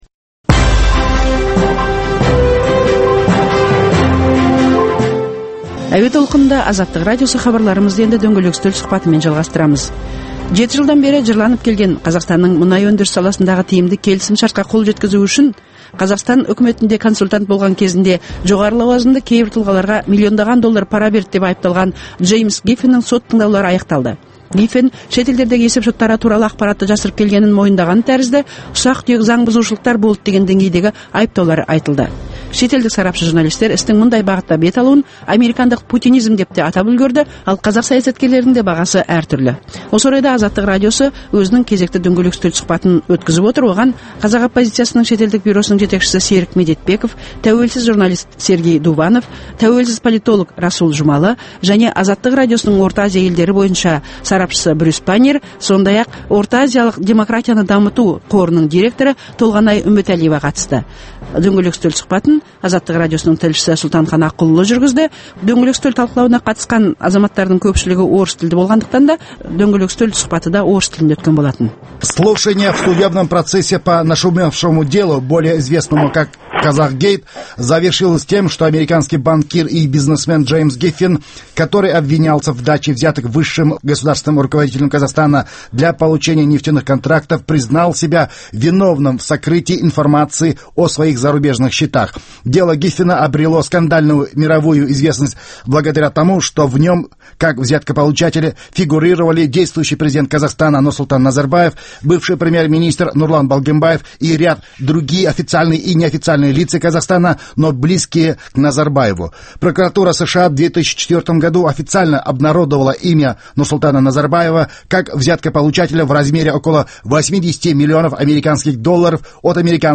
Дөңгелек үстел – Саяси және әлеуметтік саладағы күннің өзекті деген күйіп тұрған тақырыптарын қамту үшін саясаткерлермен, мамандармен, Қазақстаннан тыс жердегі сарапшылармен өткізілетін талқылау, талдау сұхбаты